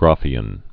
(gräfē-ən, grăfē-)